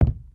sounds / material / human / step / wood03gr.ogg
wood03gr.ogg